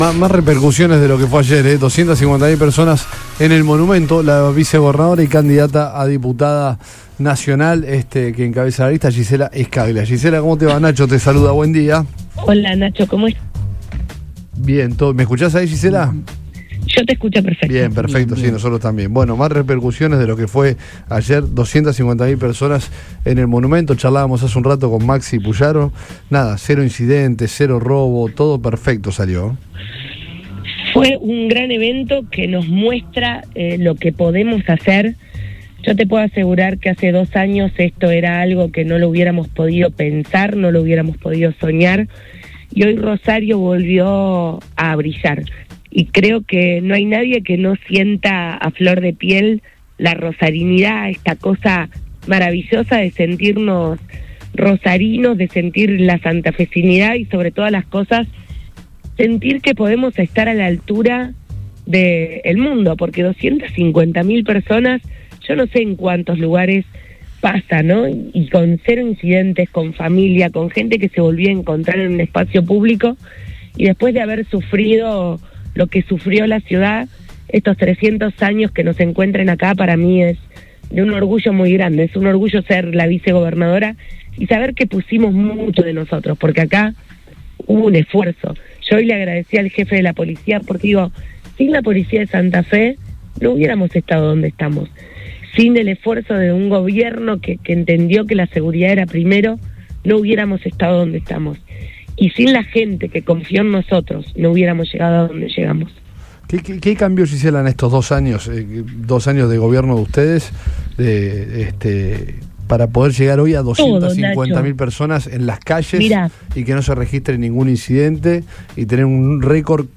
La vicegobernadora de Santa Fe y candidata a diputada nacional, Gisela Scaglia, destacó, en Todo Pasa en Radio Boing, el operativo de seguridad y el clima de celebración durante el multitudinario festejo por los 300 años de Rosario, que reunió a 250.000 personas en el Monumento a la Bandera.